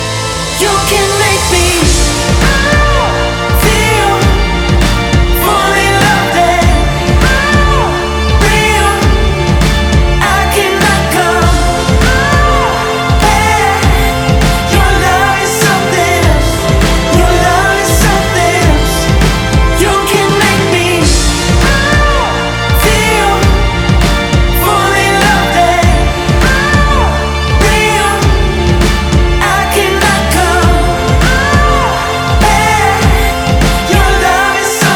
Жанр: Поп / Инди / Альтернатива